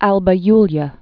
(älbə ylyə)